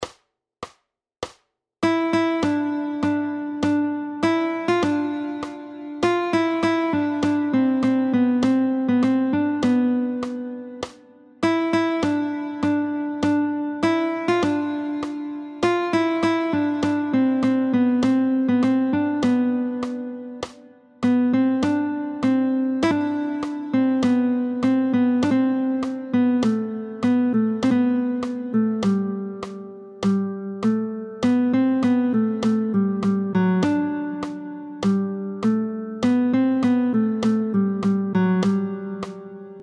√ برای ساز گیتار | سطح متوسط